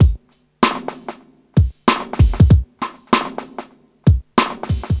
• 96 Bpm Drum Beat D# Key.wav
Free breakbeat - kick tuned to the D# note. Loudest frequency: 1333Hz
96-bpm-drum-beat-d-sharp-key-ut5.wav